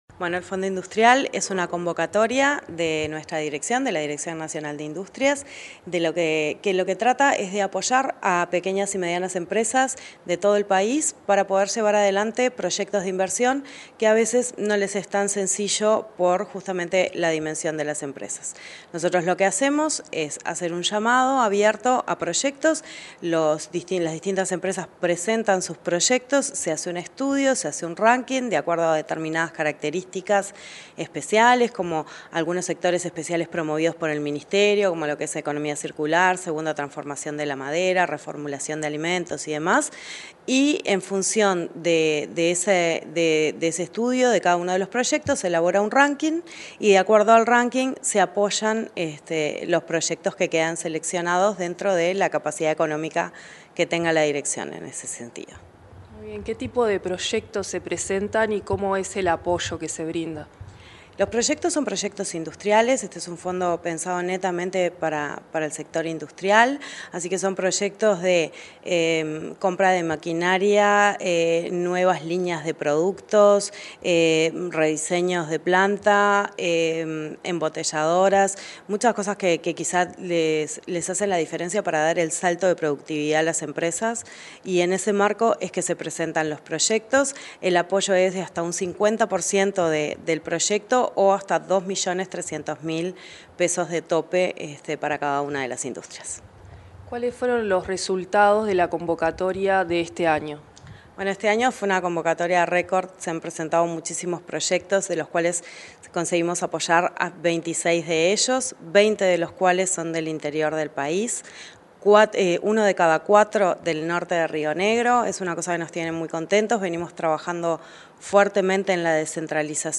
Entrevista a la directora nacional de Industrias, Susana Pecoy
La directora nacional de Industrias, Susana Pecoy, dialogó con Comunicación Presidencial acerca de la nueva edición de la convocatoria del Fondo